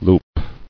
[loop]